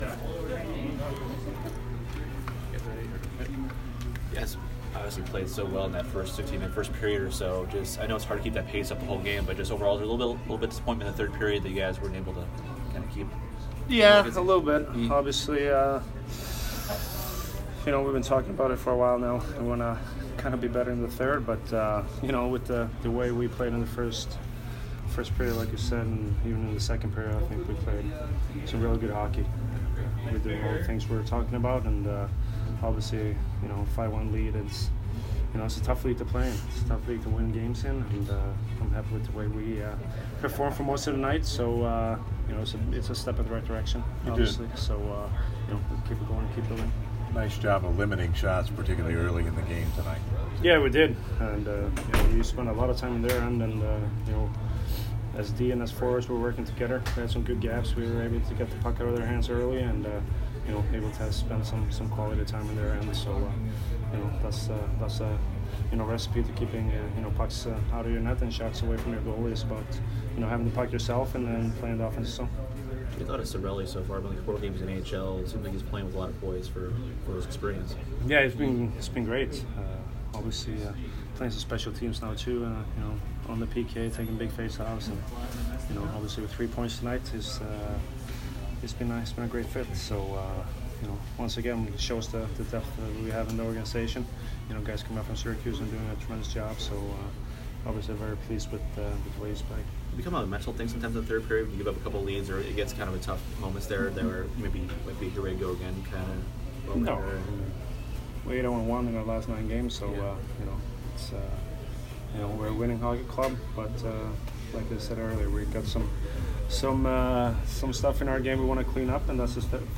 Victor Hedman post-game 3/8